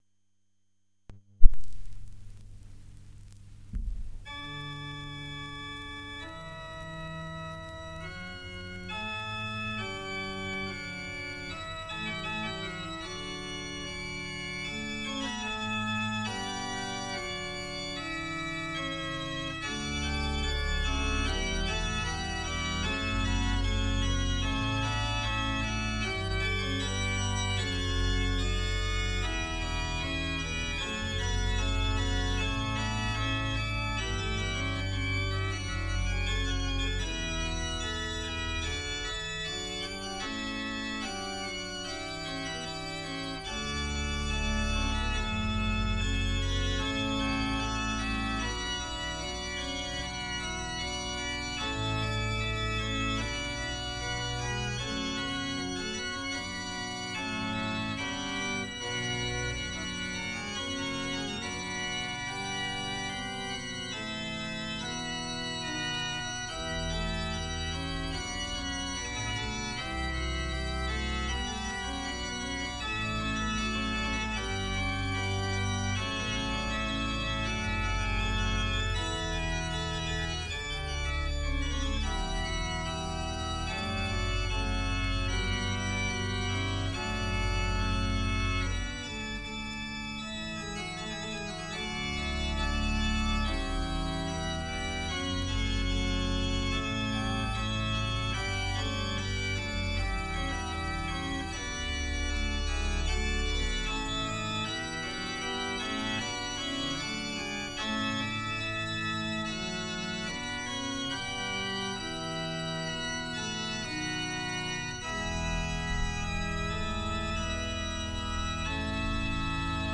06. Cavazzoni, Inno (organo).mp3